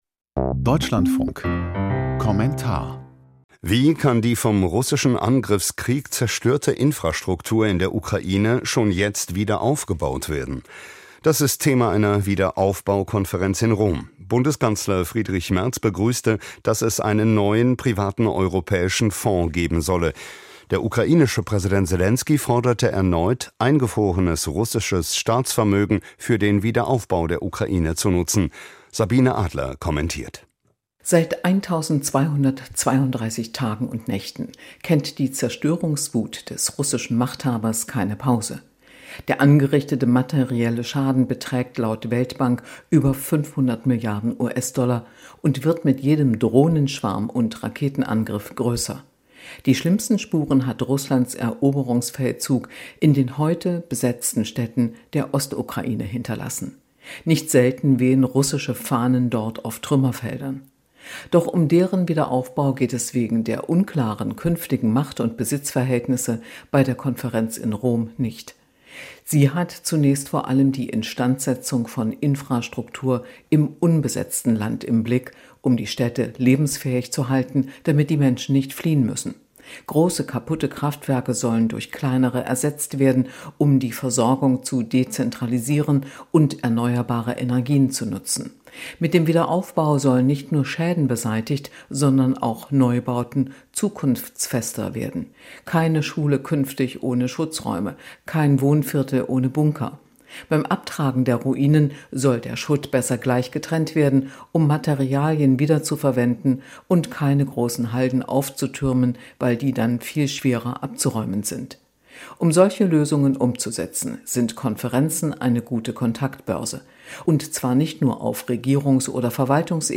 Kommentar - Ukraine-Wiederaufbaukonferenz: Wichtige Vernetzung aller Ebenen